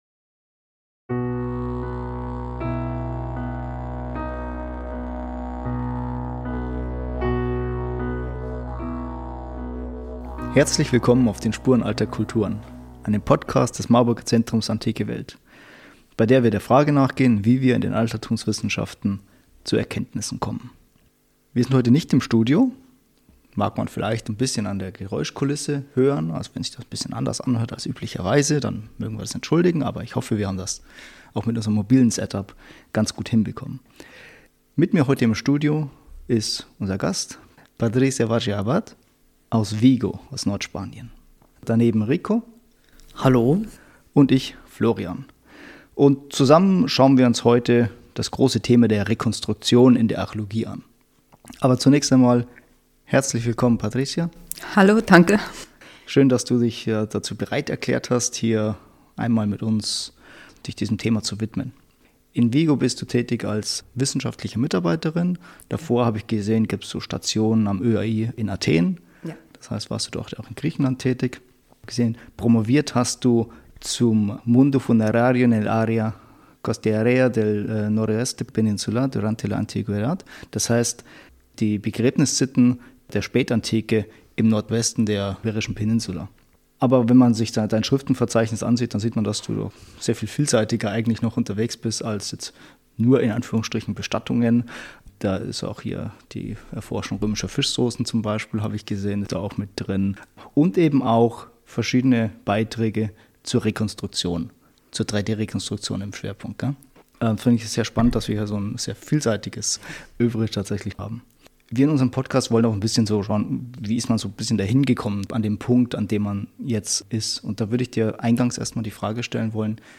Zusammen sprechen wir auch darüber, wie weit Interpretation tragen darf und und wo sie zu spekulativ wird.